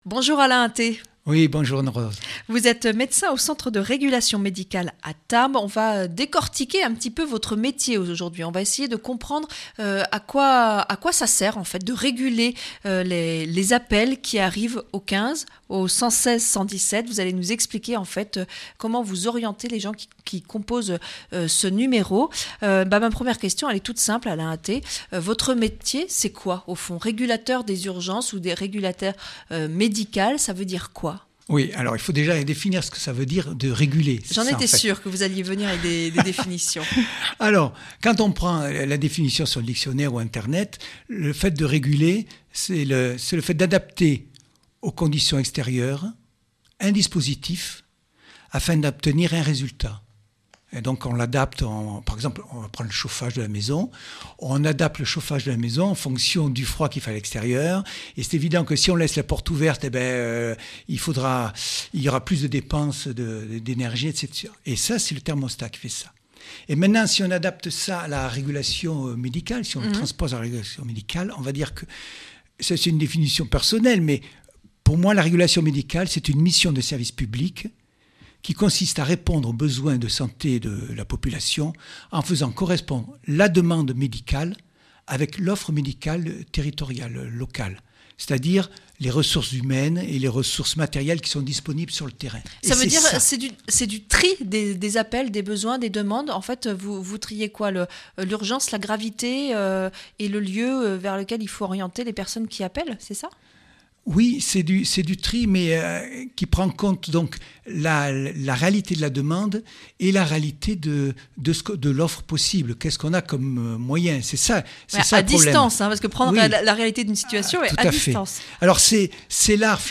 Interview et reportage